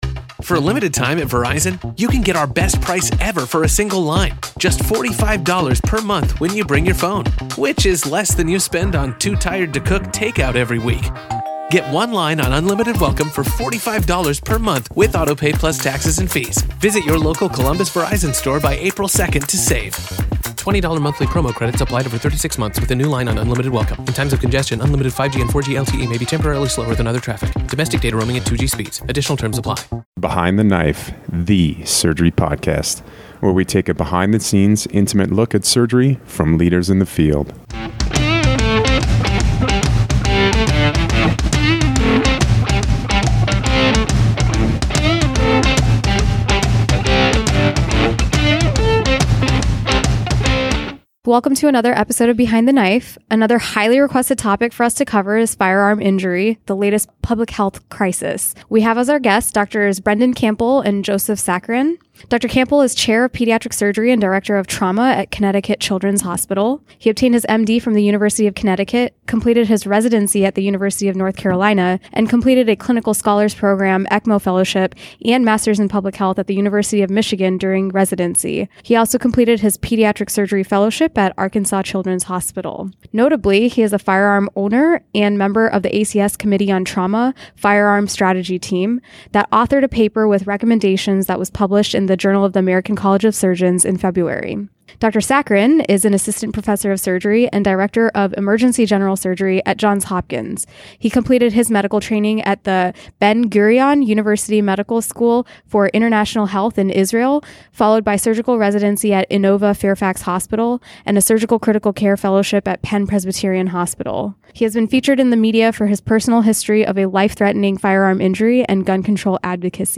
a point-counterpoint style discussion
recorded at the APSA 2019 Annual Meeting